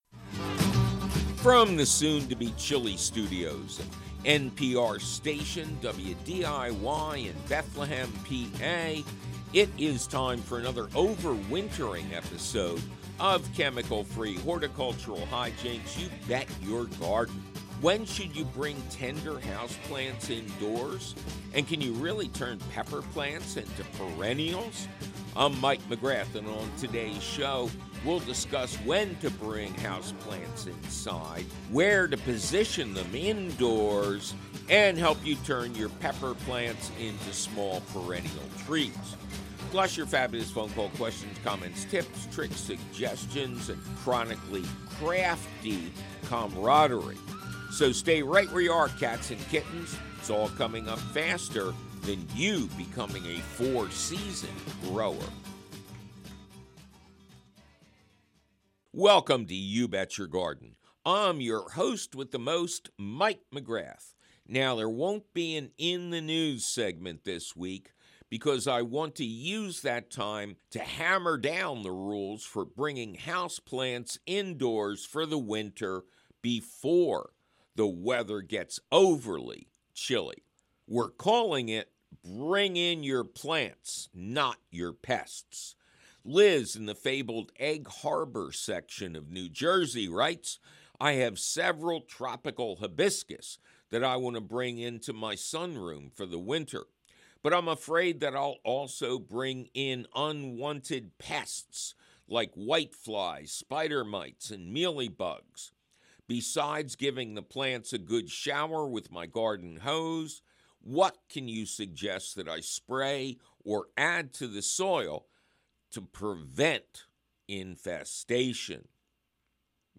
Plus your perfectly prominent phone calls!